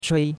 chui1.mp3